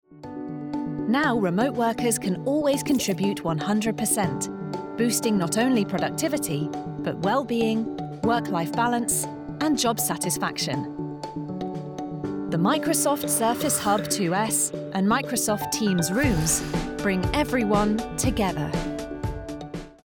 English (British)
Commercial, Natural, Accessible, Versatile, Warm
Explainer